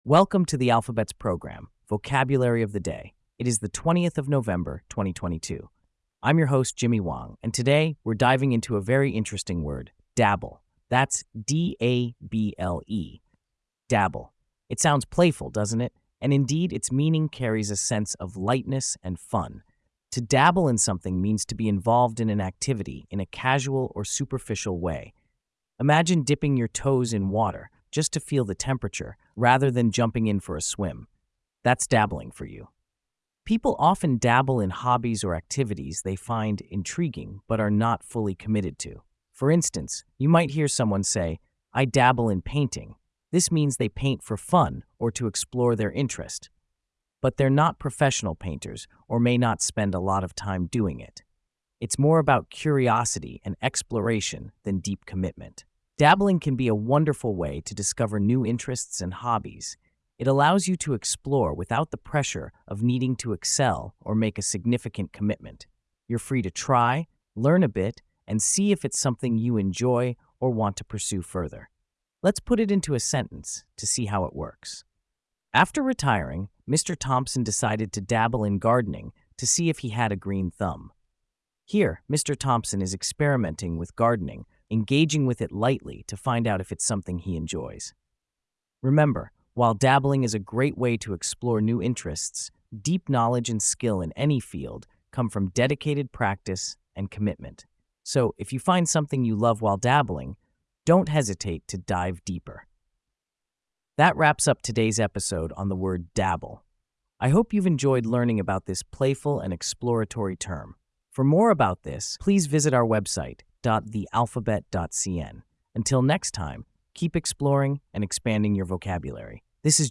发音：[ˈdab(ə)l]